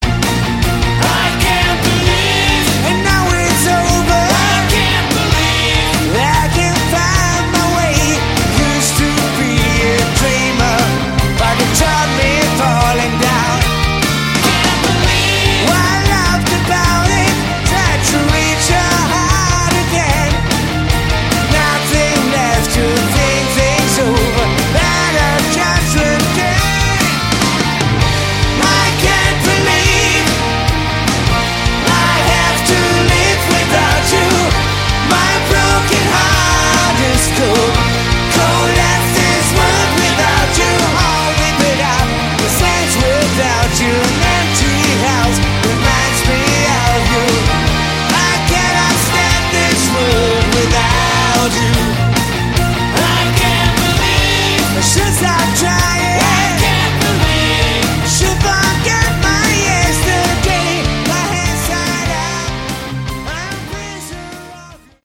Category: Hard Rock
lead vocals, guitar
bass, vocals
keyboards, vocals
drums